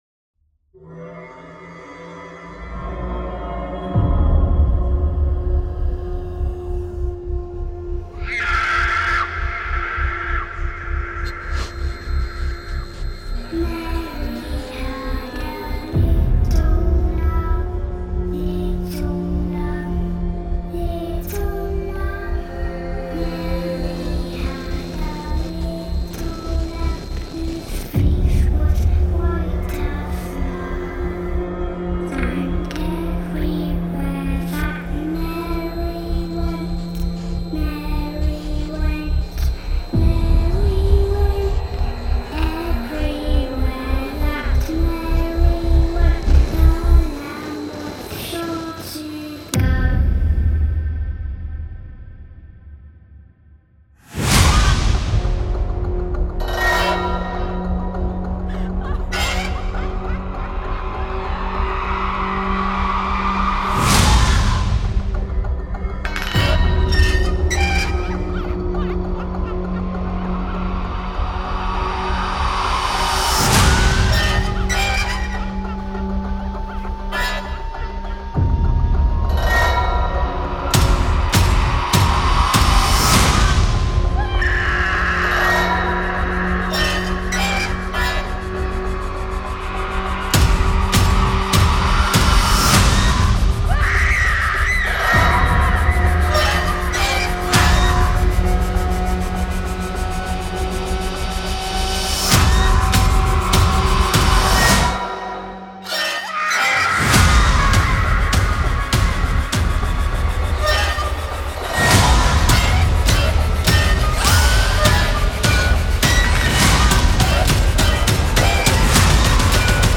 Creepy Nursery Rhymes